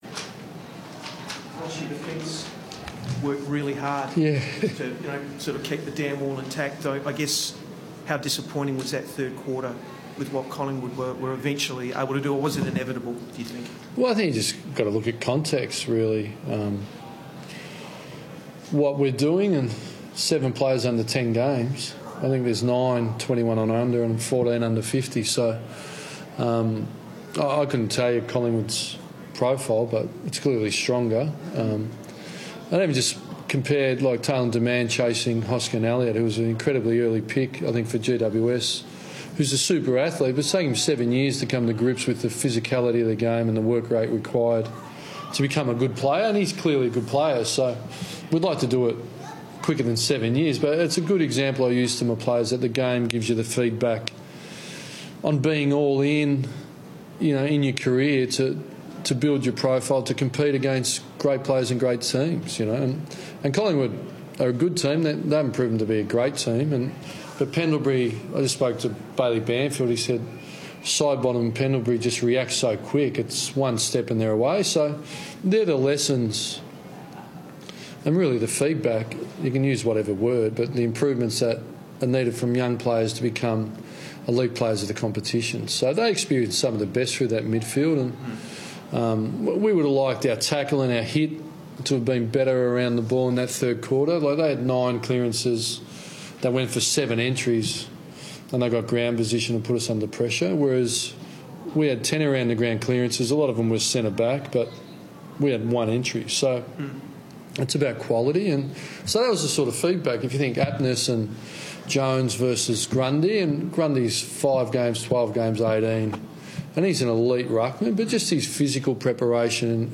Ross Lyon post-match Q&A - Round 11 v Collingwood
Ross Lyon chats to media after Rnd 11 against Collingwood